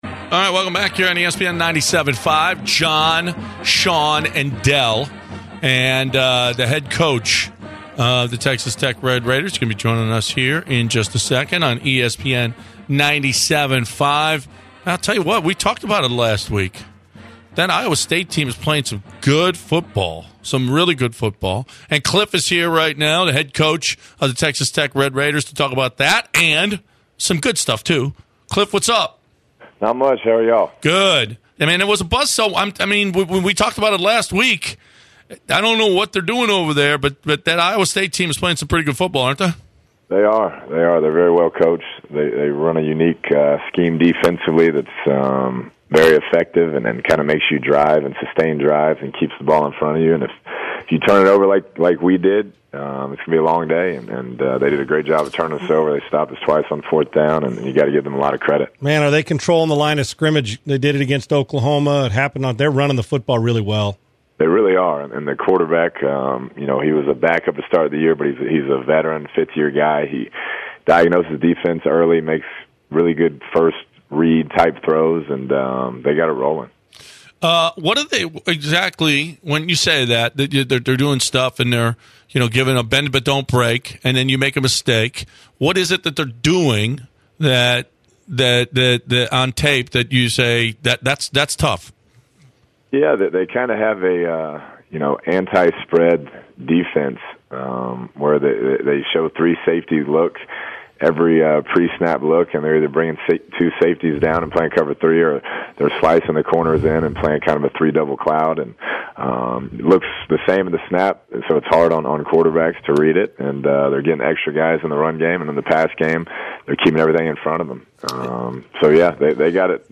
10/23/2017 Kliff Kingsbury Interview